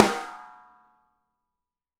ST DRYSN3.wav